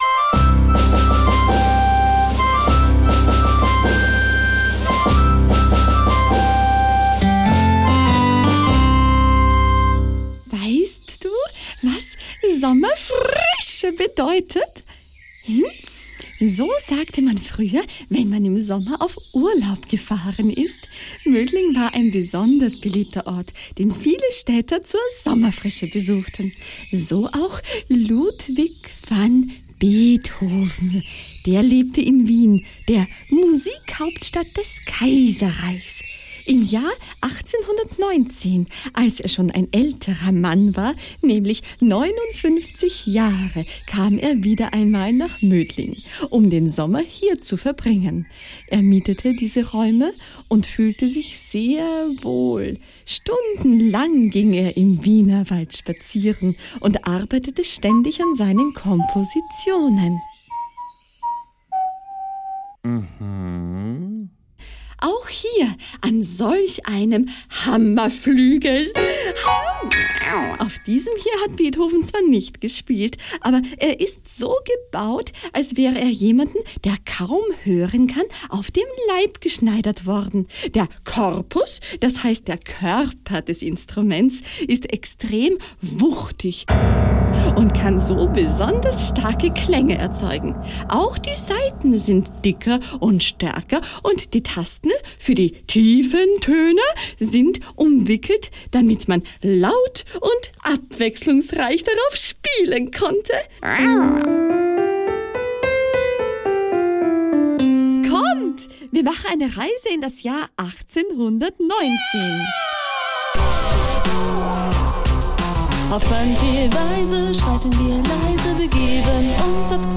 Beethoven: Stimme tief, mit leichtem Westfälischem Akzent
Der Bäckersbub Franz: Knabenstimme